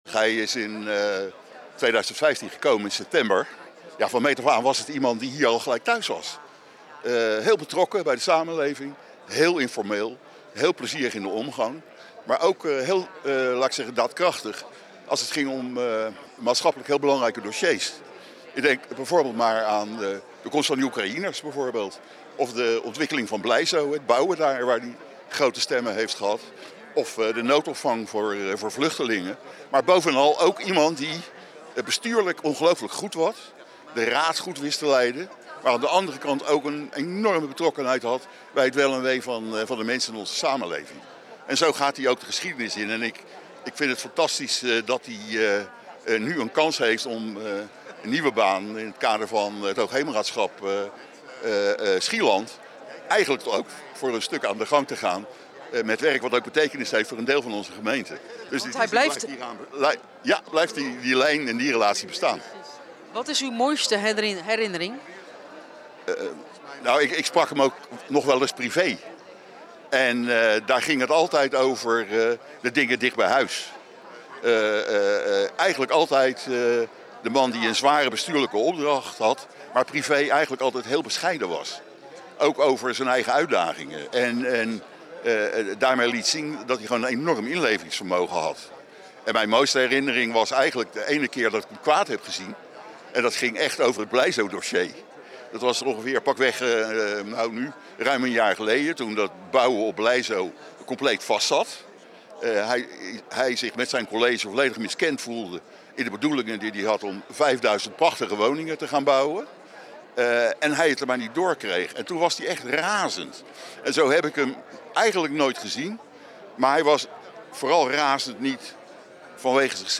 De onderscheiding werd uitgereikt tijdens zijn afscheidsreceptie.